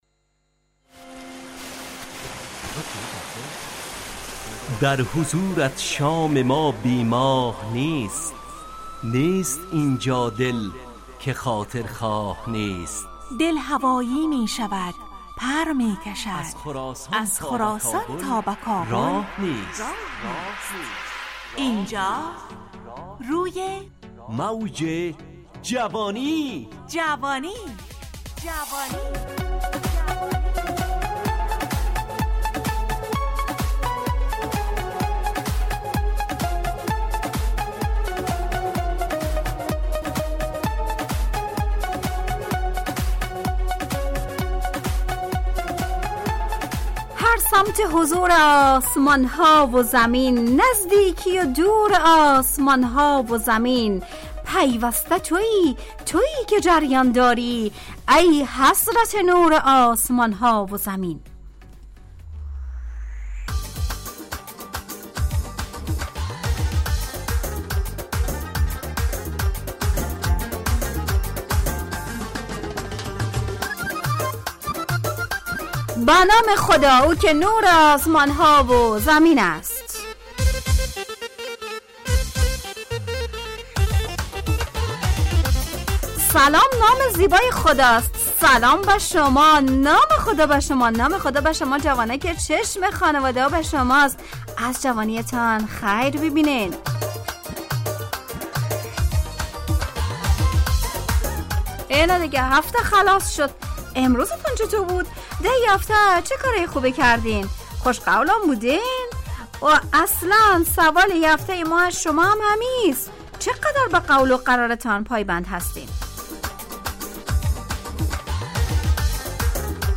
روی موج جوانی، برنامه شادو عصرانه رادیودری.
همراه با ترانه و موسیقی مدت برنامه 70 دقیقه . بحث محوری این هفته (قول و قرار) تهیه کننده